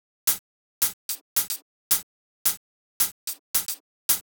35 Hihat.wav